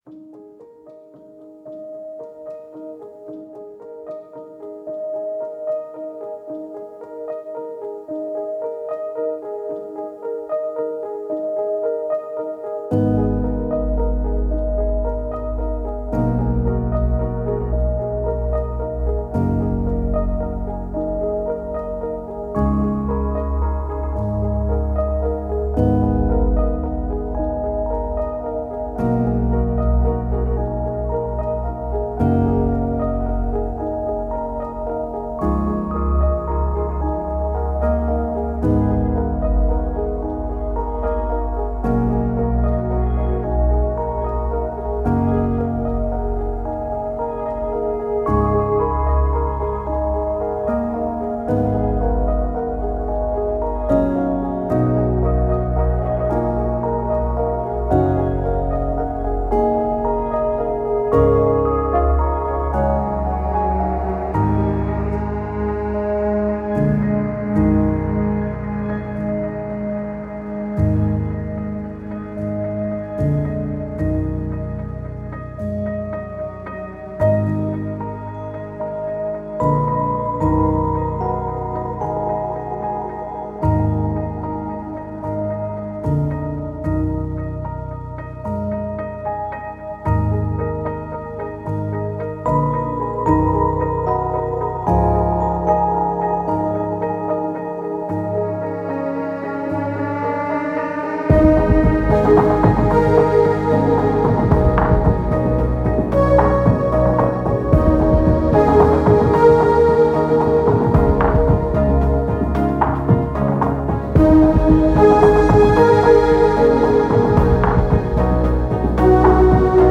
Remote solitary melodies, skim vast emotional landscapes.